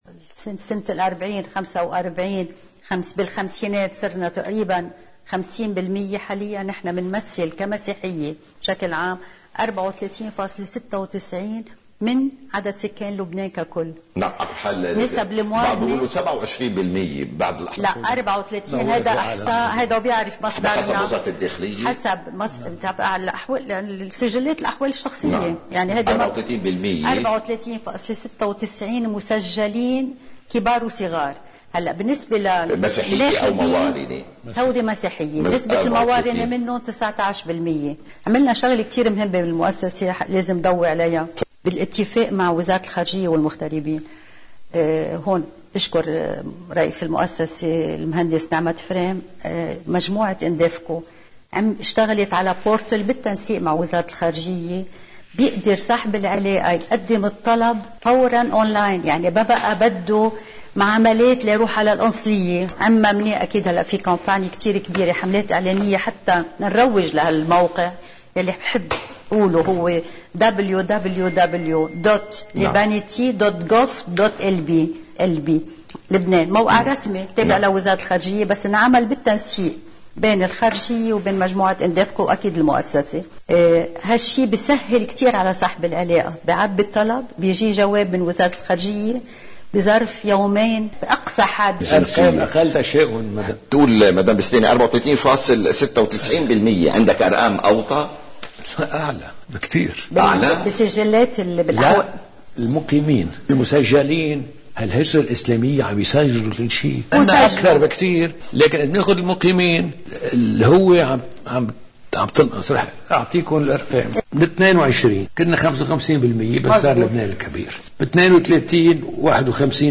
مقتطف من حديث المديرة العامة للمؤسسة العامة للإنتشار هيام البستاني: